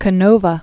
Ca·no·va
(kə-nōvə), Antonio 1757-1822.